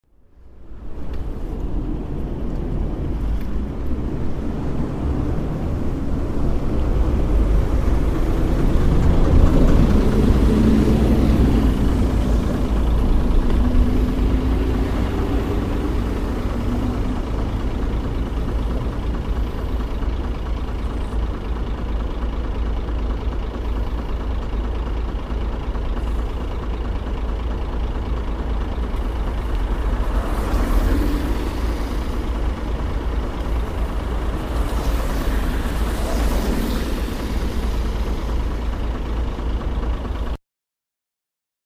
Field Recording #6
2) A truck’s engine running, waiting for the green light, other smaller cars passing.